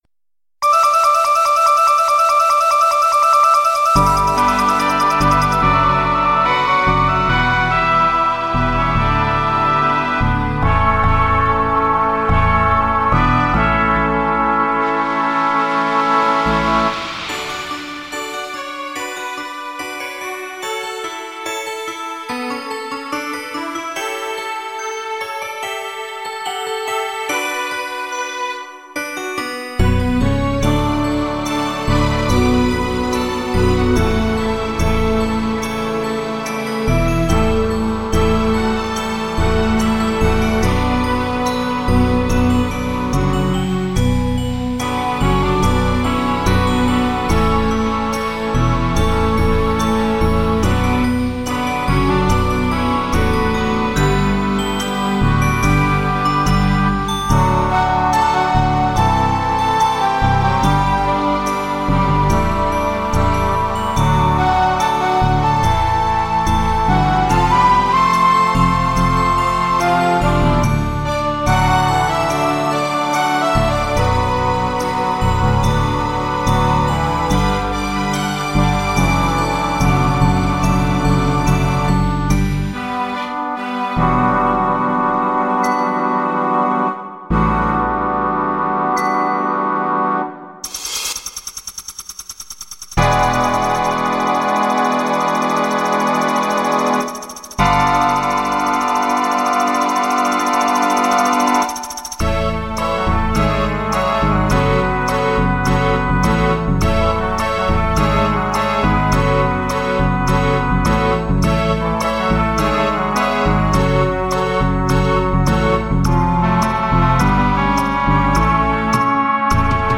Музыкальная композиция из трёх частей для оркестра